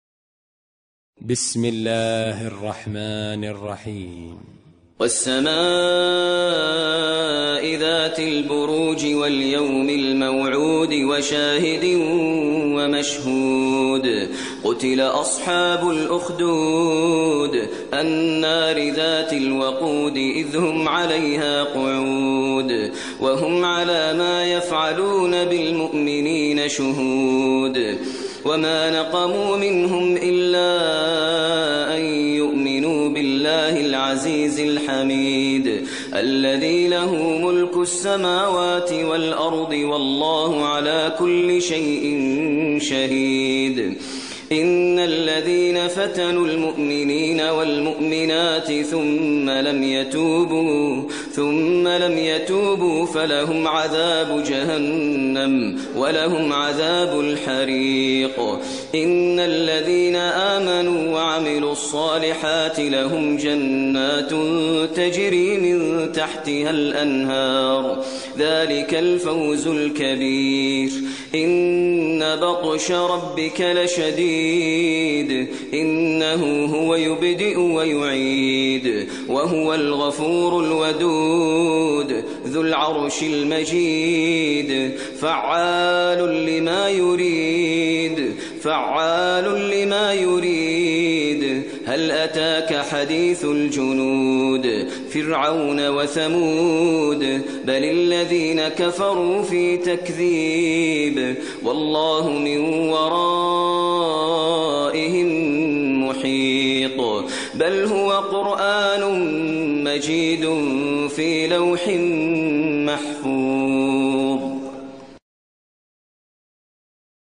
ترتیل سوره بروج با صدای ماهر المعیقلی
085-Maher-Al-Muaiqly-Surah-Al-Burooj.mp3